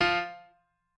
piano7_2.ogg